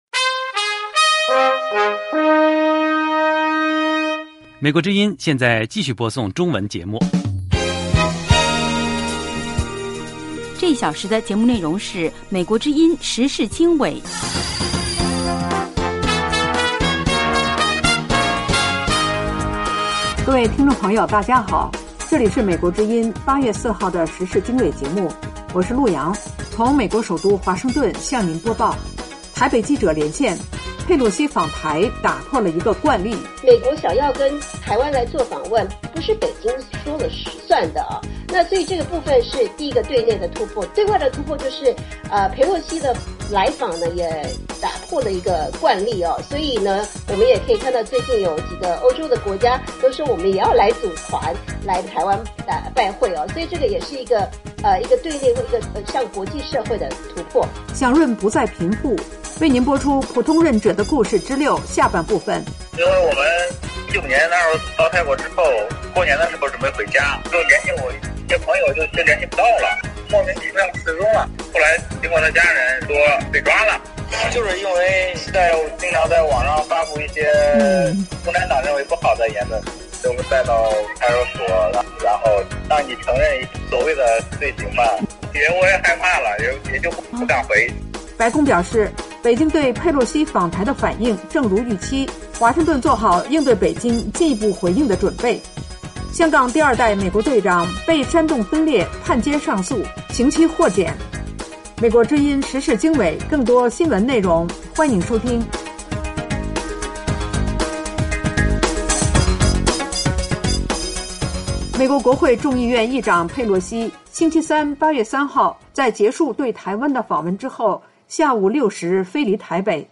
时事经纬(2022年8月4日)：1/台北记者连线：佩洛西访台打破了一个惯例。2/白宫：北京对佩洛西访台的反应正如预期，华盛顿做好应对北京进一步回应的准备。